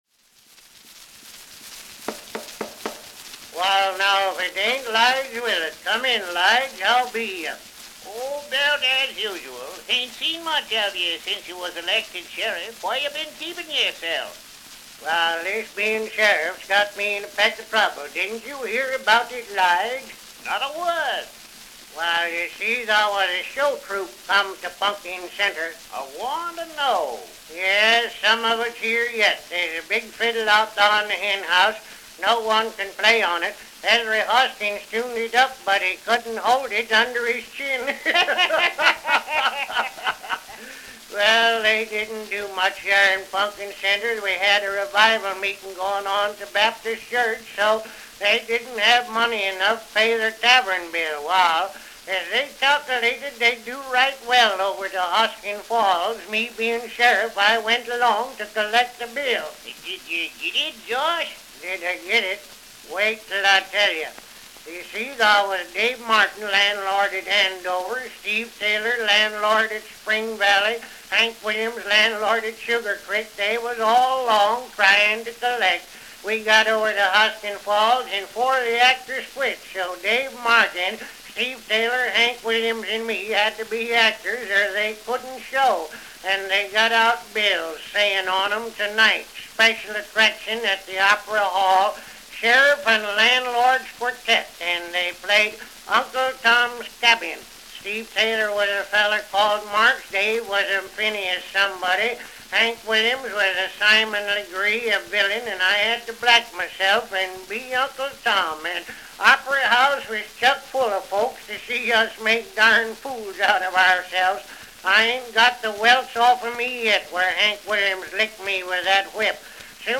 Edison 4-minute cylinder…”The Show Troupe At Pumpkin Centre”